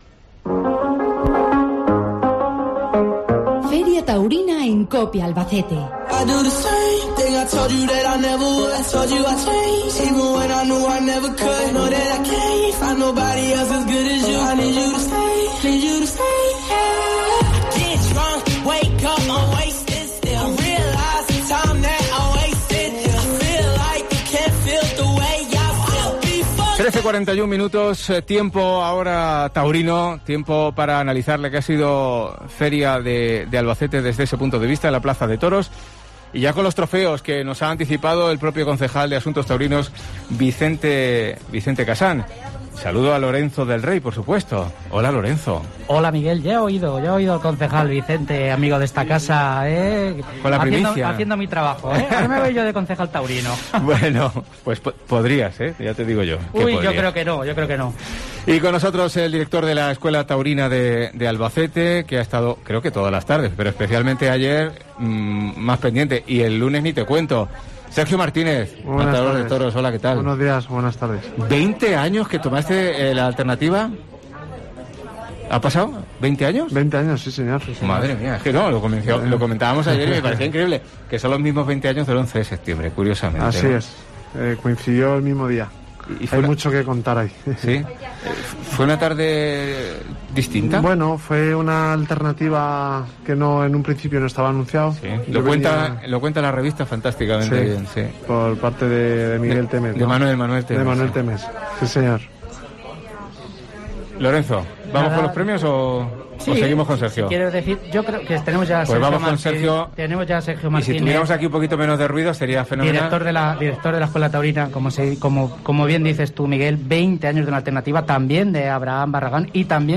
Tertulia Taurina en COPE